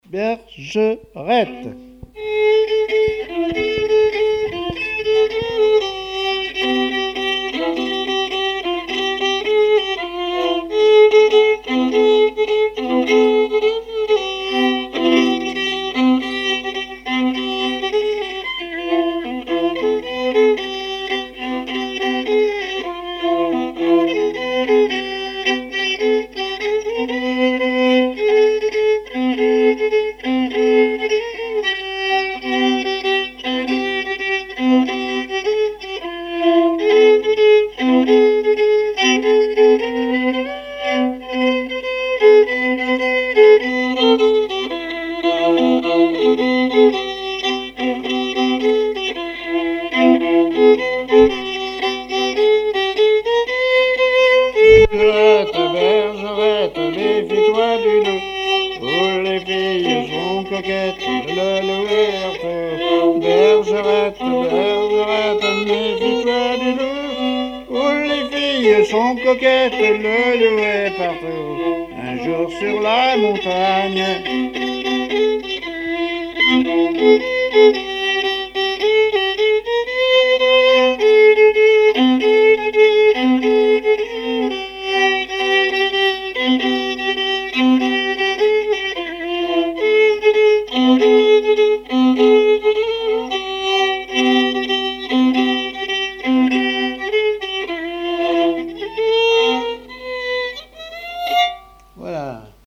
répertoire musical au violon
Pièce musicale inédite